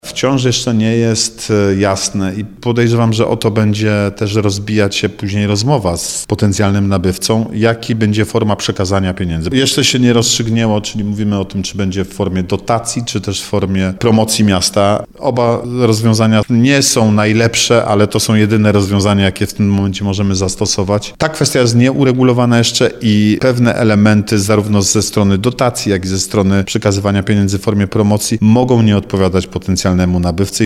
– Duet sonduje możliwość przejęcie stu procent akcji spółki MKS Sandecja – mówi Artur Bochenek, zastępca prezydenta Nowego Sącza.